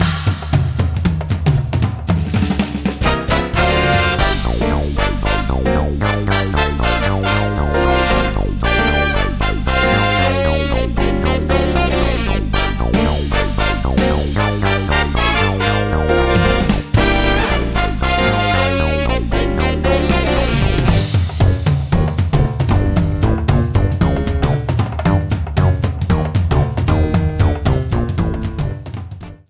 Game rip